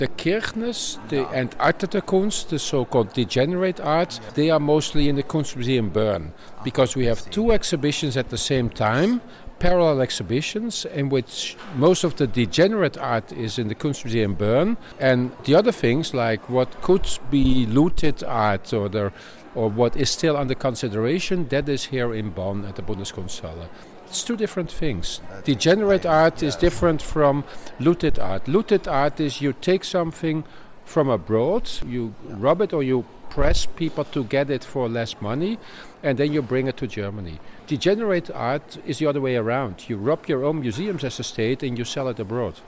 Un interviu în exclusivitate